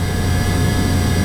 whine.wav